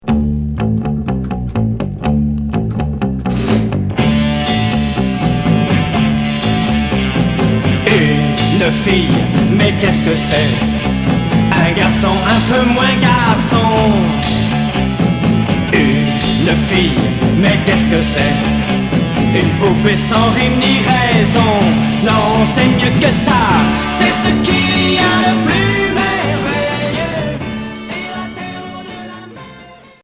Freakbeat